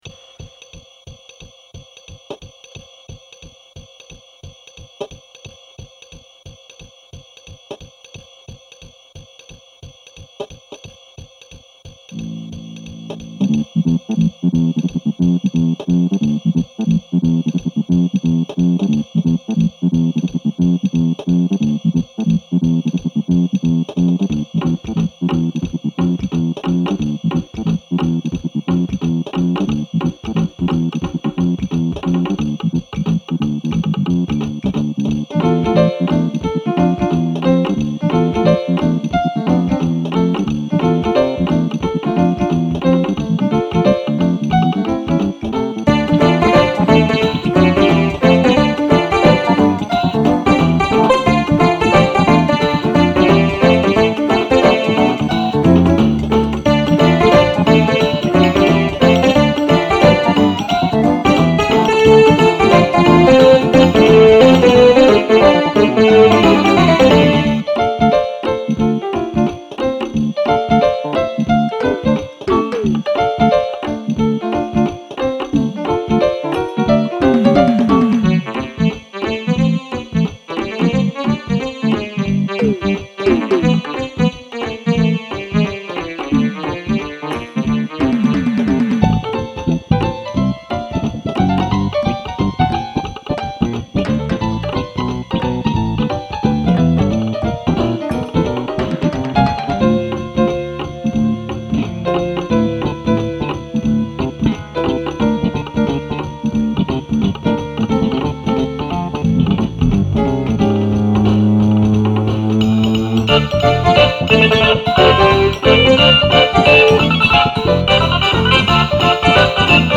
This is an extremly Lo-Fi Jazz Funk track. Lots of crunch and grit to this.
I used sounds from old cheap keyboards and came up with some grooves.